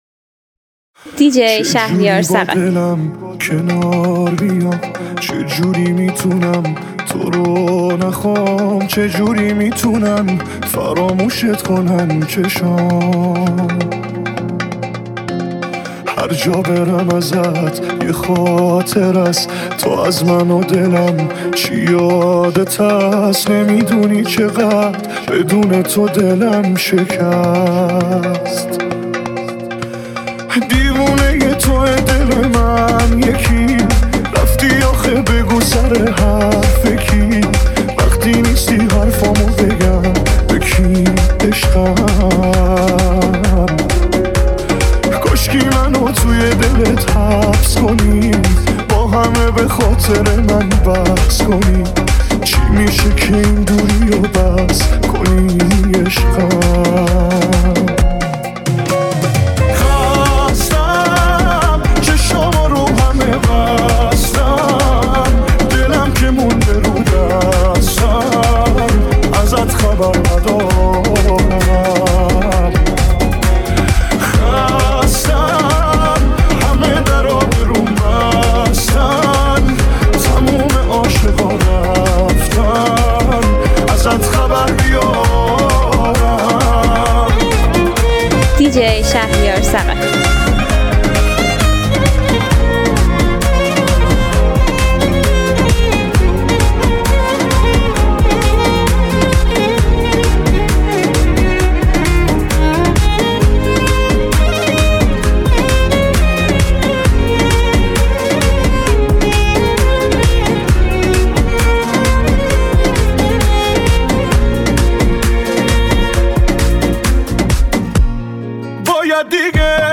آهنگ بیس دار اهنگ سیستمی ریمیکس غمگین